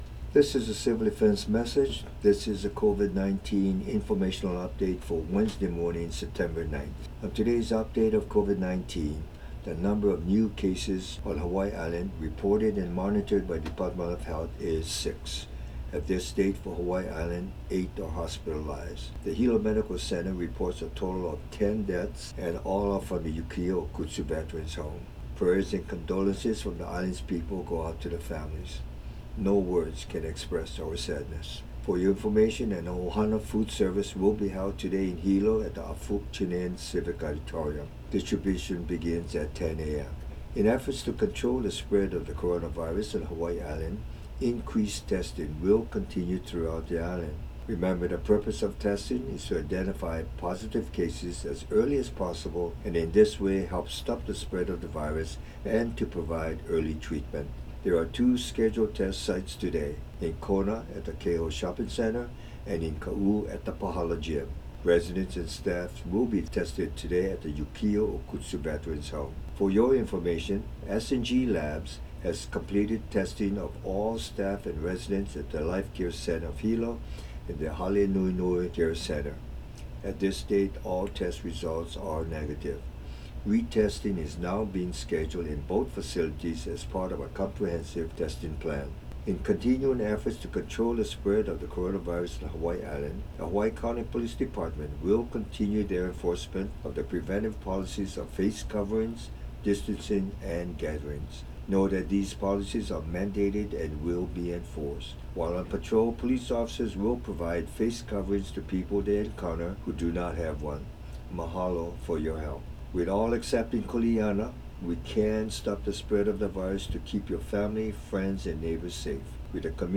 From the Hawaiʻi County Civil Defense radio message issued on Wednesday morning: